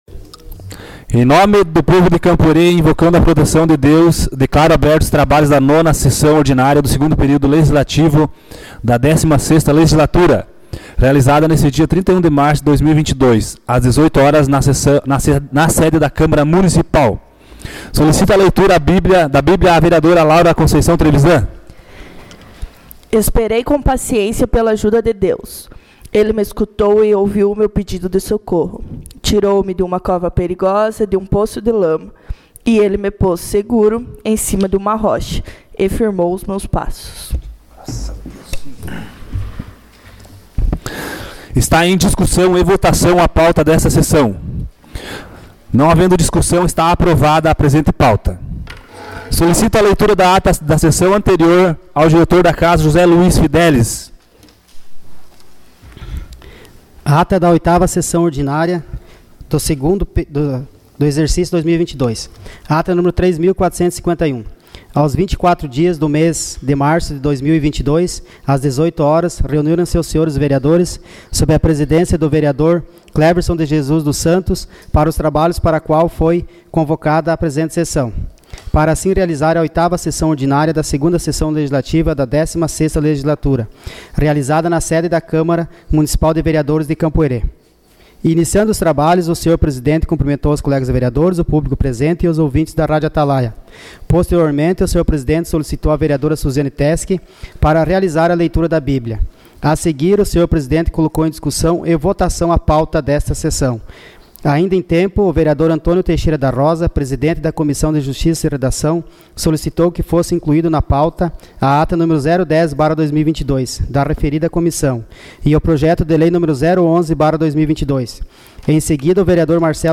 Sessão Ordinária dia 31 de março de 2022